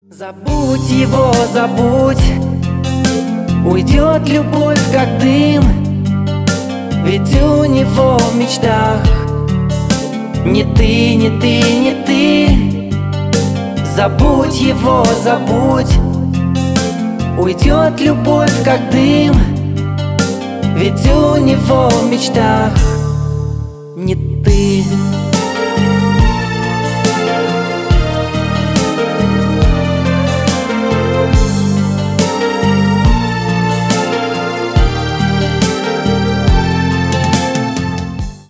грустные , поп